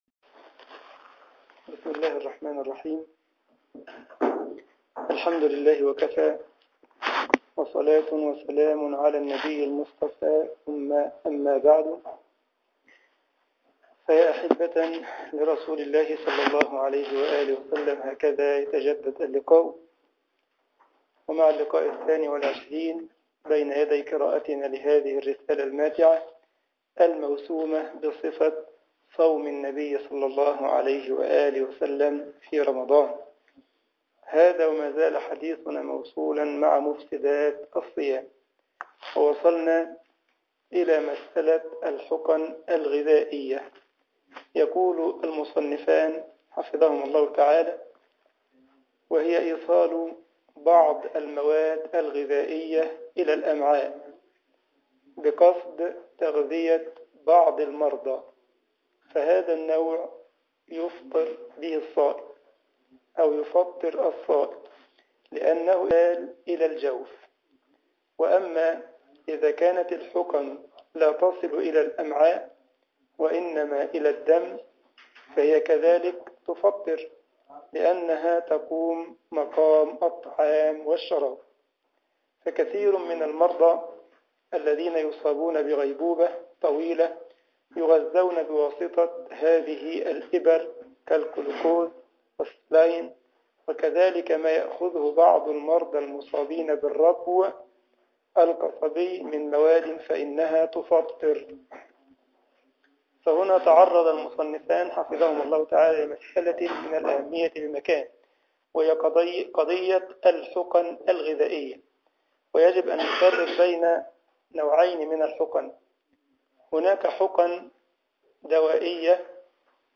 مسجد الجمعية الاسلامية بالسارلند المانيا